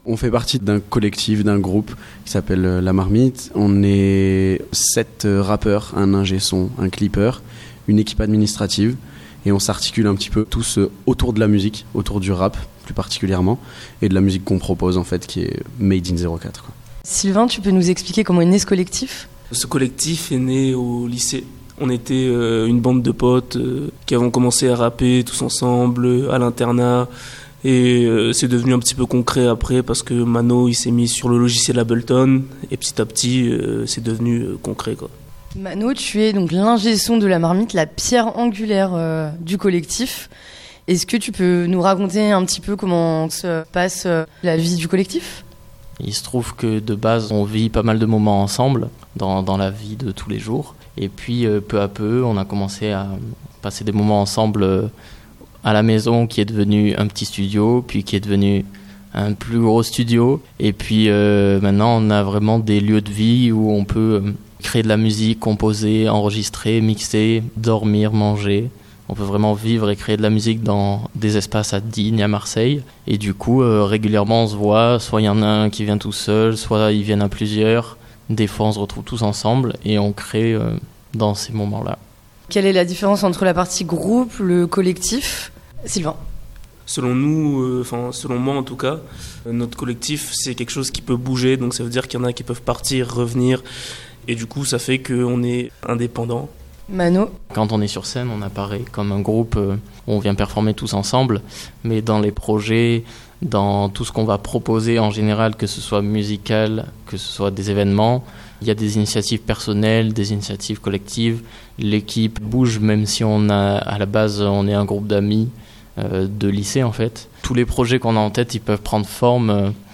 au Studio de Fréquence Mistral Digne